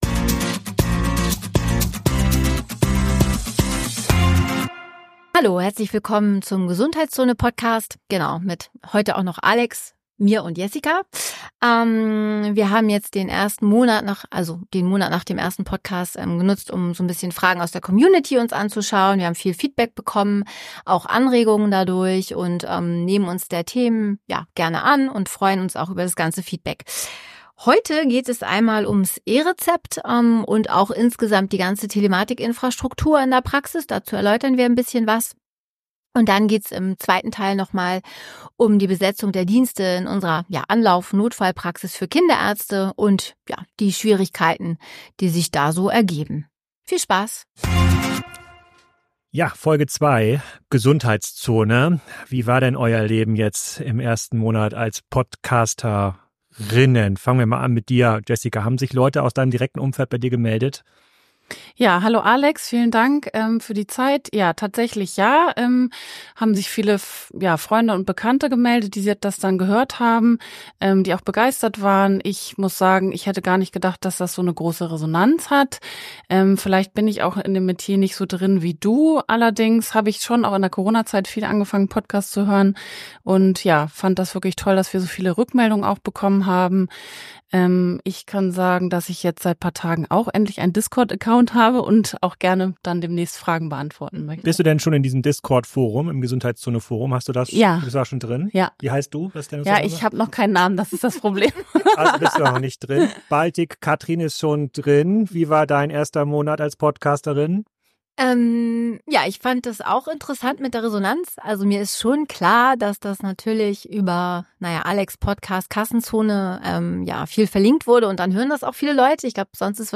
G#2 E-Rezept Chaos und Notfalldienst Drama ~ GESUNDHEITSZONE // zwei Kinderärzte über Probleme und Lösungen in ihrem Job Podcast